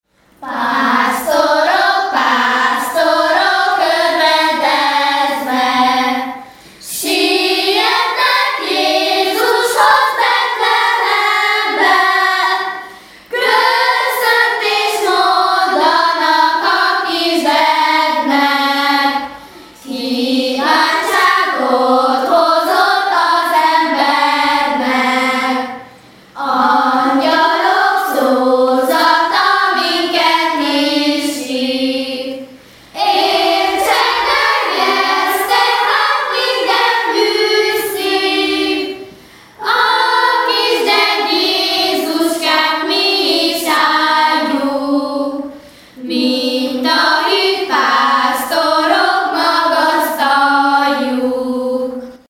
Ajándékul fogadjanak el néhány karácsonyi dalt iskolánk gyermekkórusa előadásában.